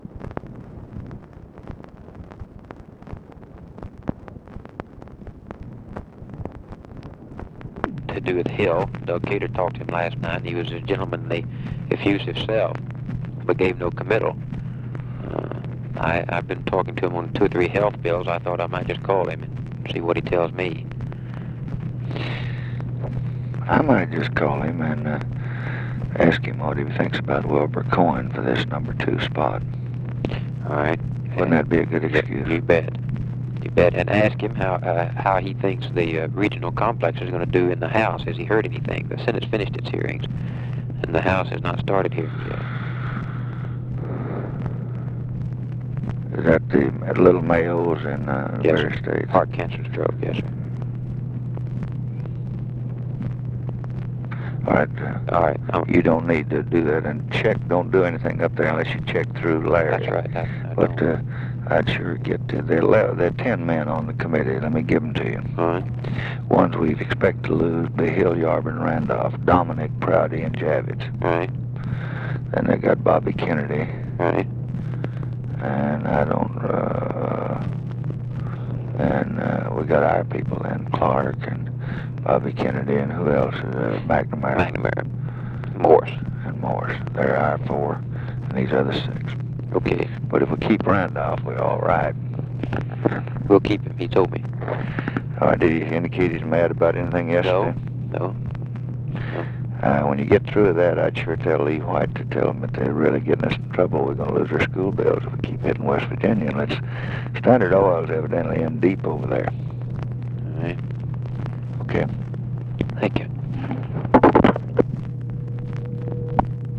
Conversation with BILL MOYERS, April 1, 1965
Secret White House Tapes